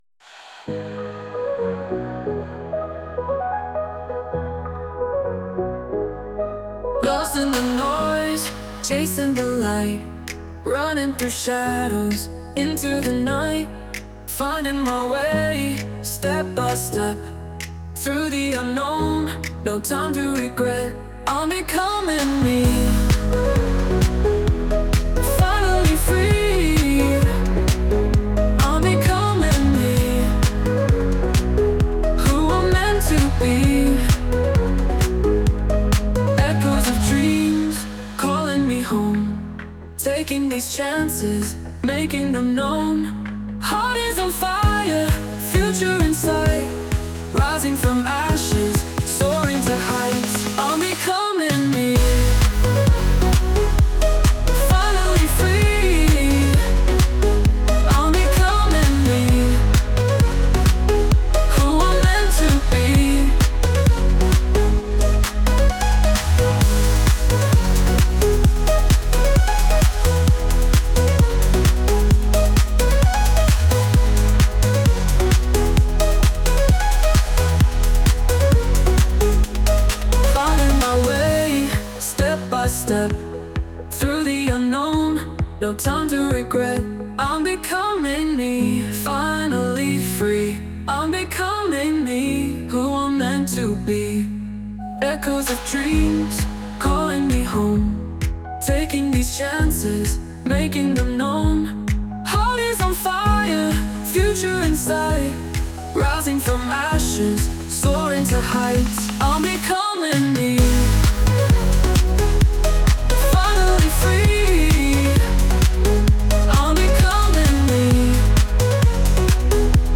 The AI-generated songs